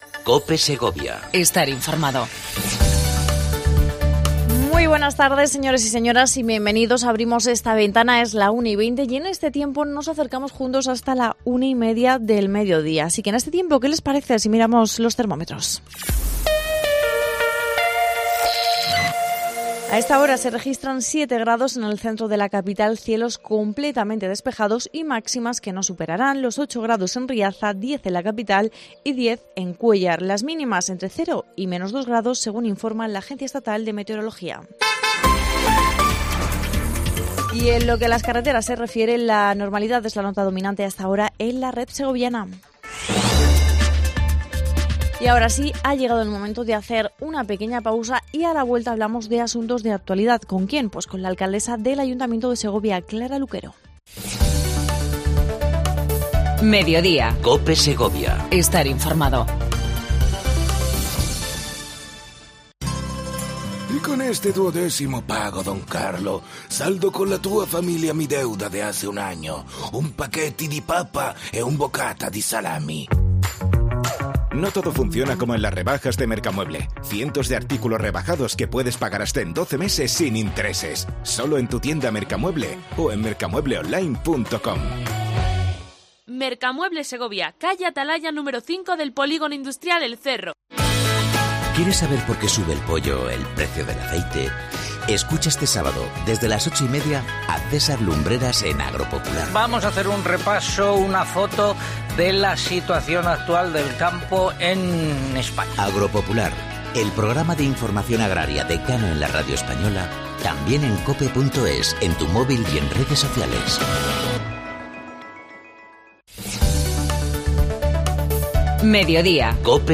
Entrevista mensual a Clara Luquero, Alcaldesa de la capital segoviana con la que hablamos de la actualidad de la provincia.